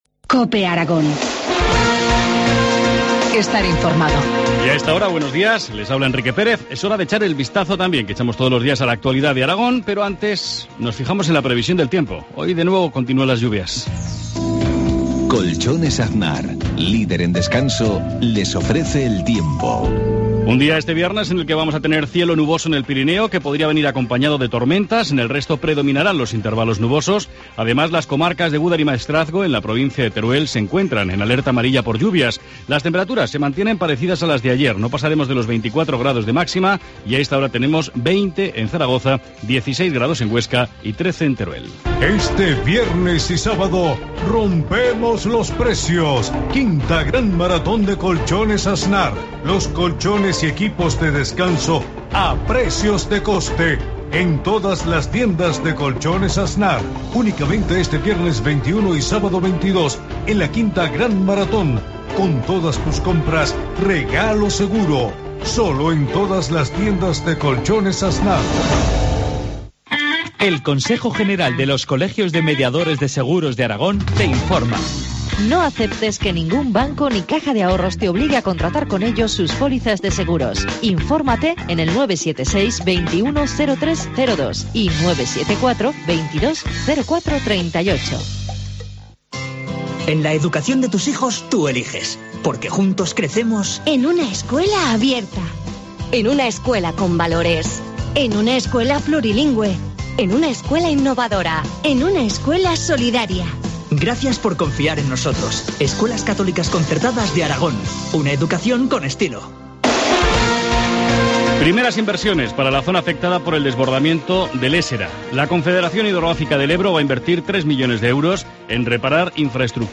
Informativo matinal, viernes 21 de junio, 7.53 horas